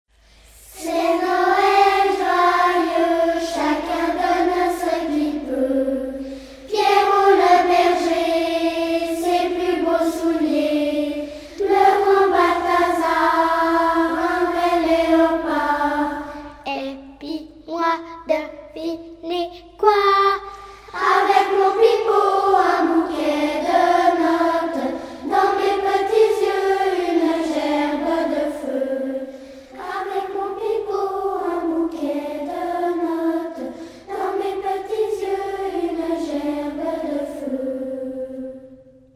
2004 - 2005 - Choeur d'enfants La Voix du Gibloux